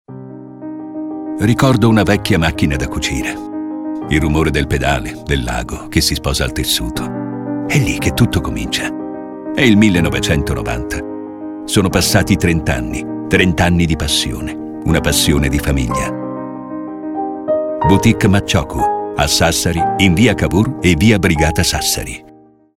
Spot Anniversario